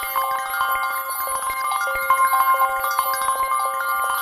time_warp_healing_spell_loop1.wav